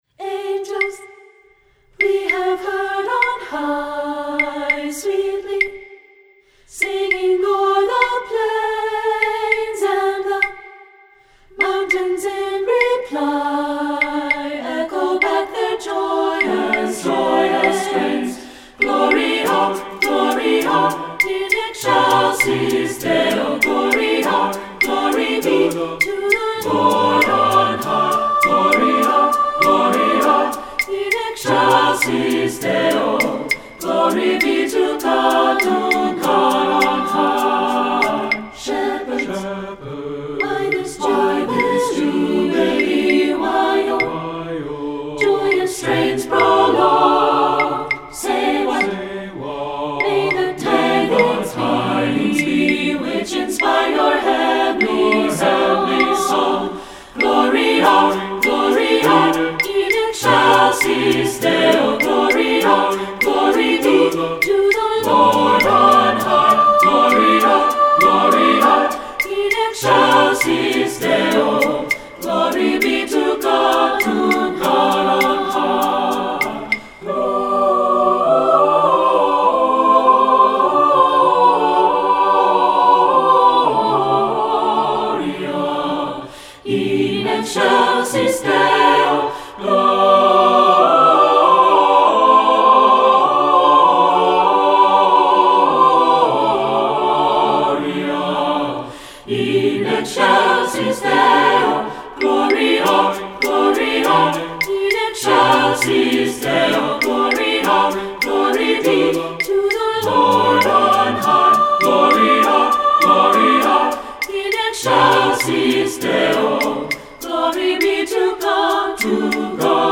Voicing: SATB a cappella and Optional Claves